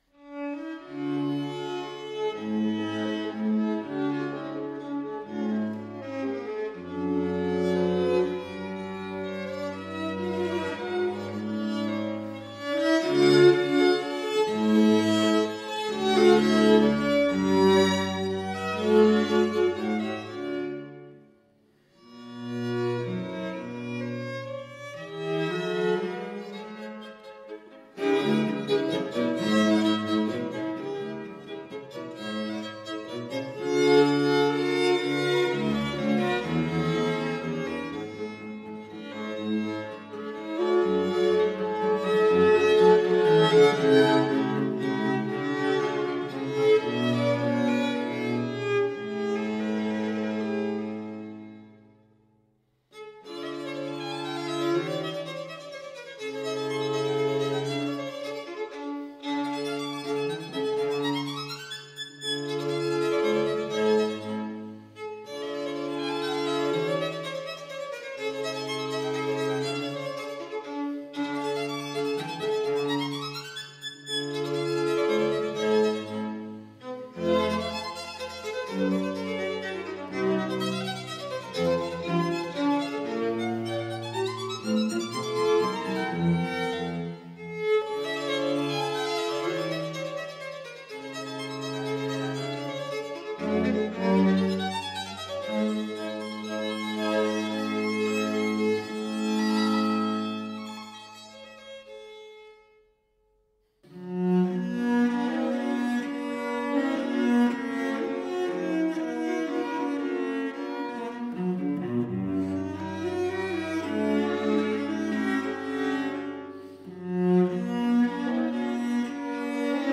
Soundbite Movt 2